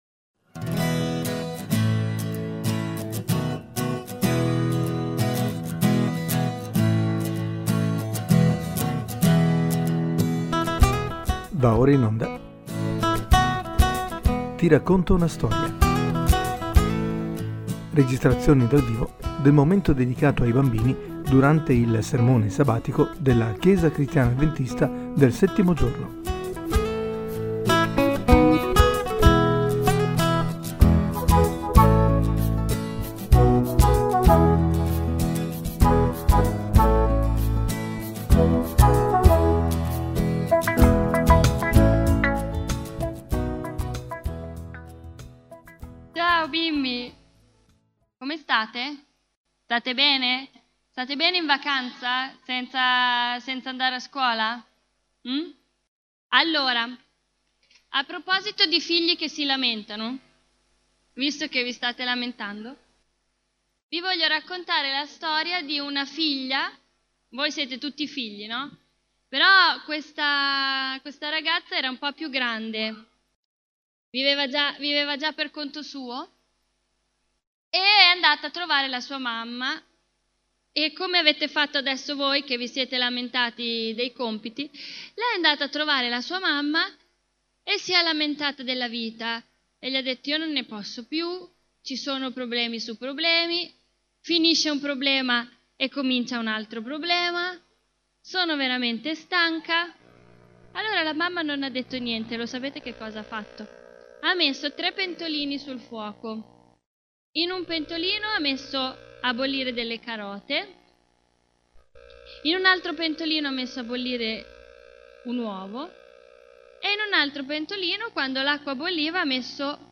Ti racconto una storia 34: (22.07.2017) Registrazioni dal vivo della storia dedicata ai bambini durante il sermone sabatico della chiesa cristiana avventista del settimo giorno di Forlì.